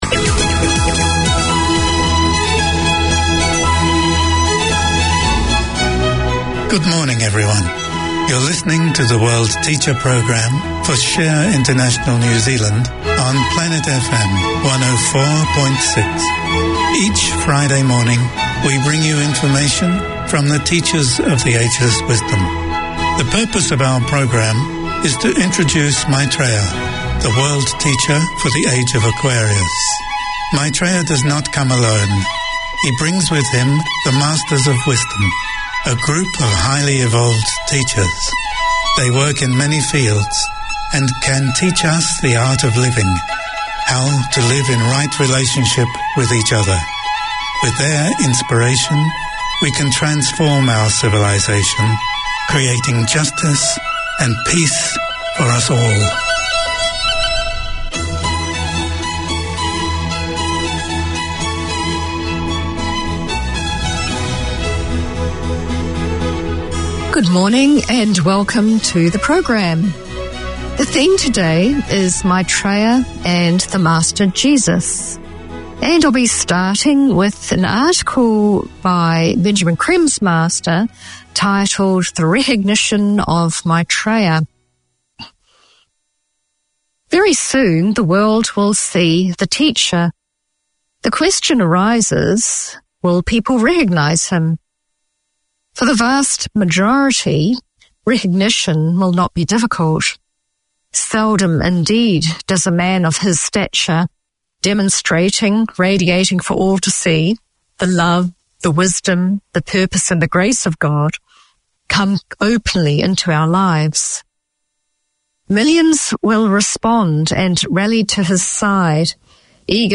Community Access Radio in your language - available for download five minutes after broadcast.
Deco is the passion of the presenters of this programme that explores the local and global Deco scene, preservation and heritage, the buildings, jewellery and furnishings with interviews, music, notice of coming events; a sharing of the knowledge of Club Moderne, the Art Deco Society of Auckland.